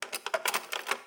SFX_Cutlery_08.wav